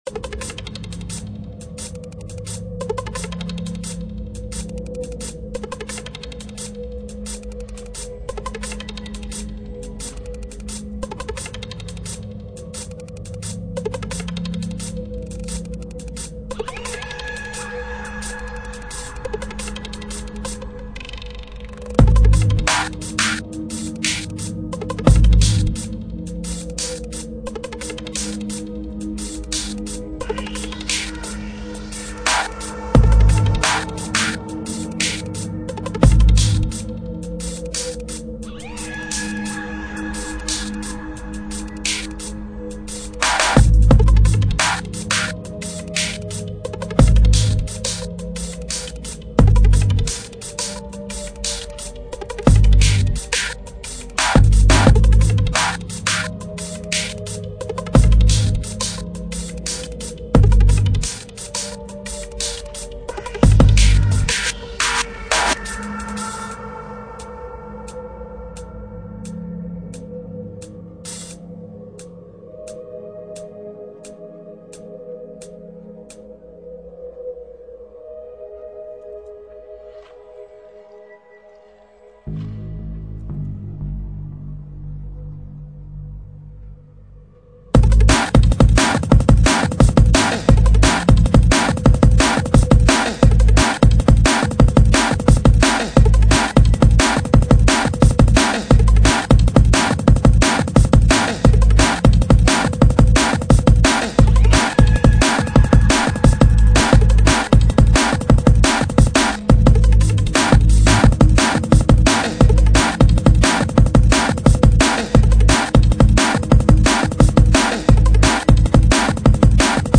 Drum'n'bass , Hardstep , Techstep